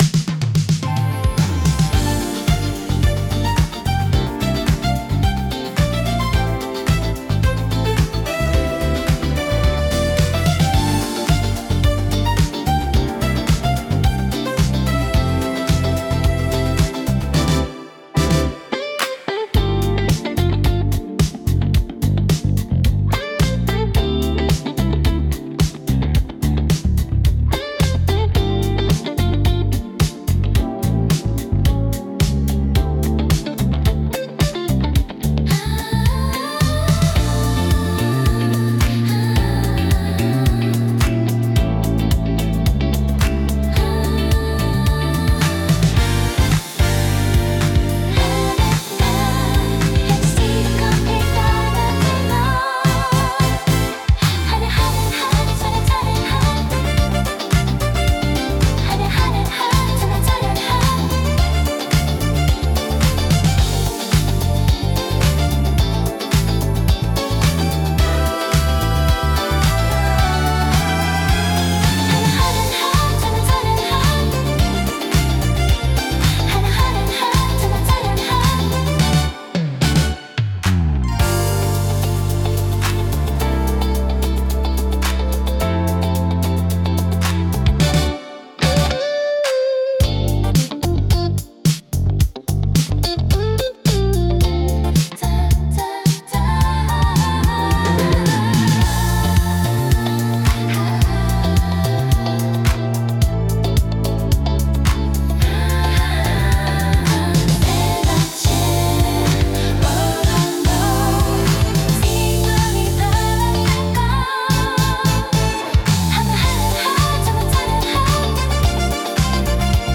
シティポップは、1970～80年代の日本で生まれたポップスの一ジャンルで、都会的で洗練されたサウンドが特徴です。
聴く人にノスタルジックかつモダンな気分を届けるジャンルです。